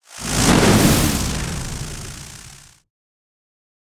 spell-impact-lightning-2.mp3